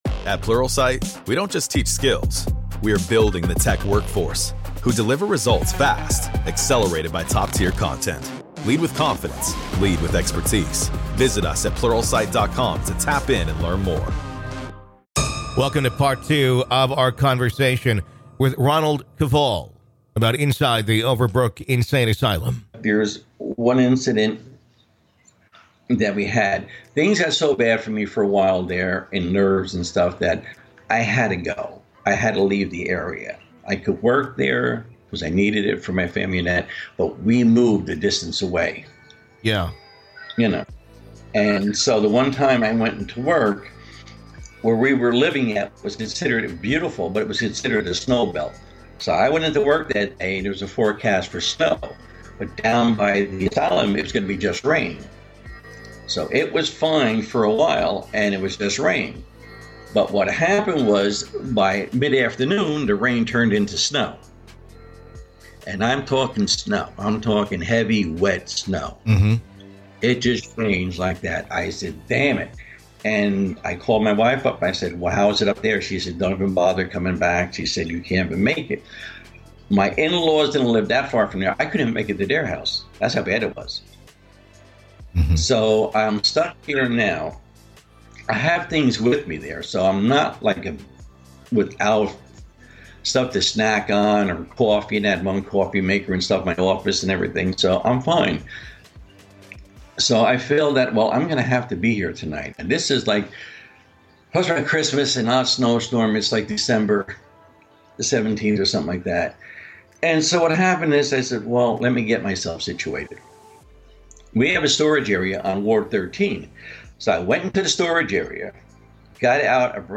From time warps to conversations with patients who have passed, and much more. This is Part Two of our conversation.